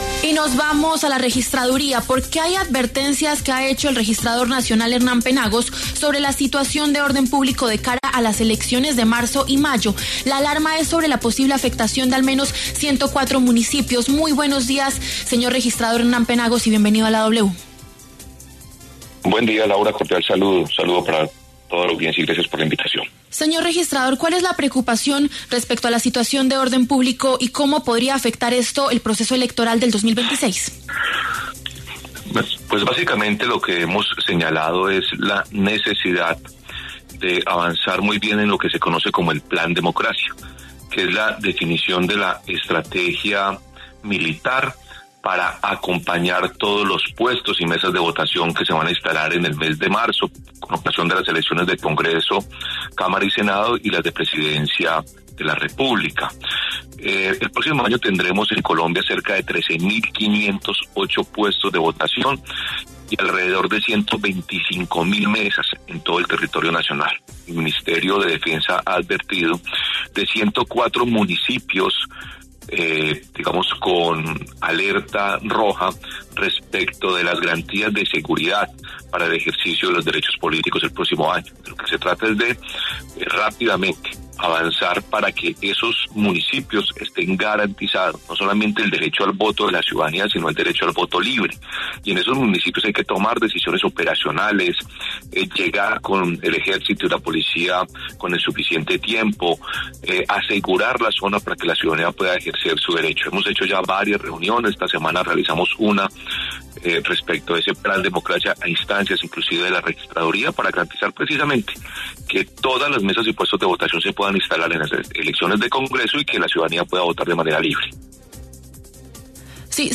El registrador nacional, Hernán Penagos, conversó con W Fin de Semana sobre lo que se viene para las elecciones del 2026, destacando fechas clave y problemáticas de orden público en el país.